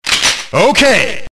okey metal slug 3 Meme Sound Effect
Category: Games Soundboard